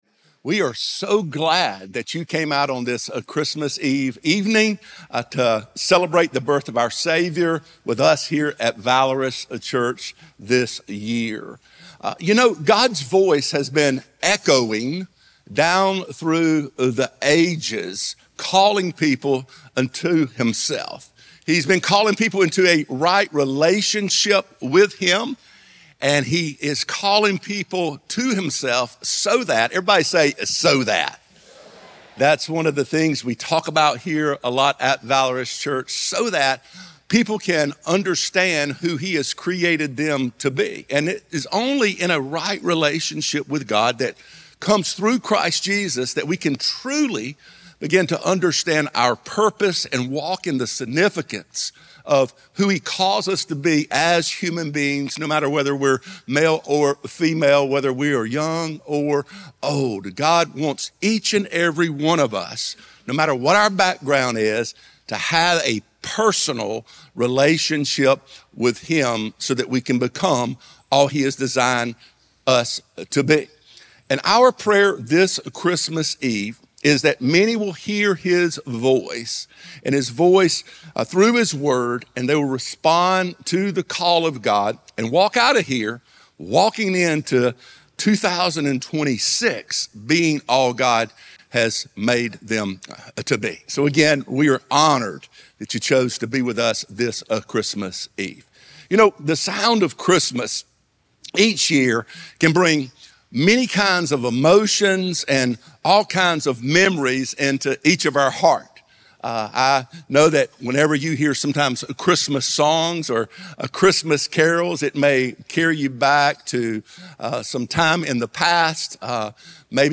He is known for his ability to captivate and challenge the audience by delivering the Word of God in a way that is relevant to our world today.